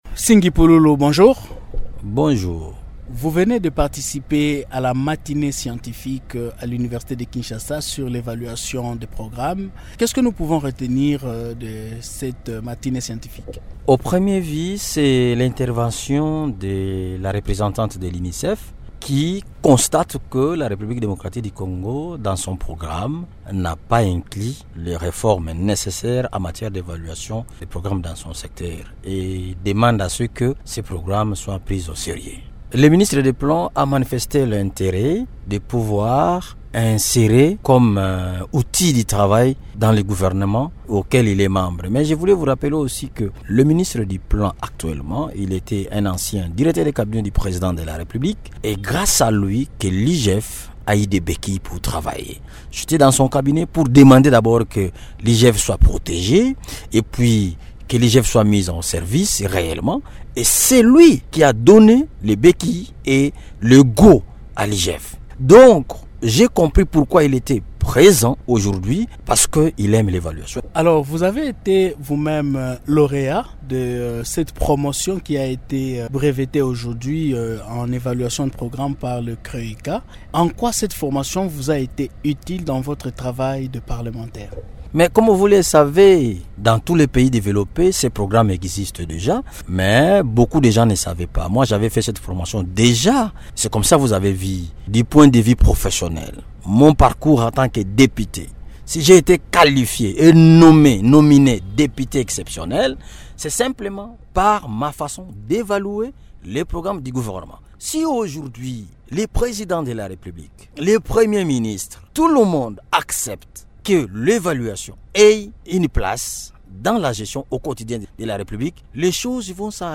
Nsingi Pululu est l’invité de Radio Okapi de ce vendredi 23 aout.